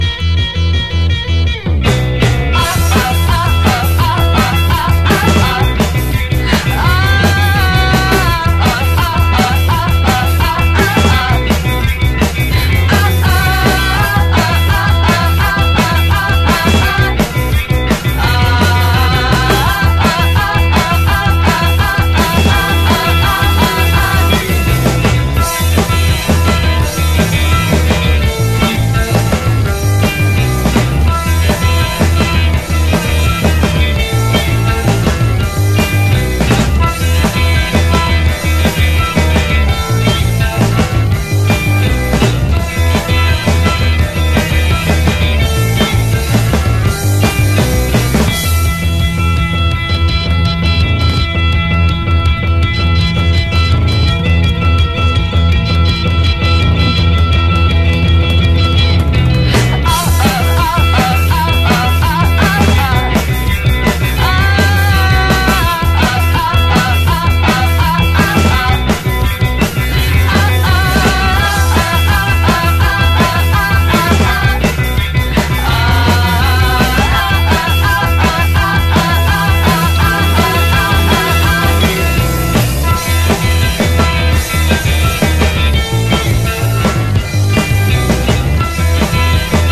NEO-ACO/GUITAR POP